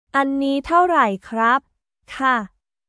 アンニー タオライ クラップ/カー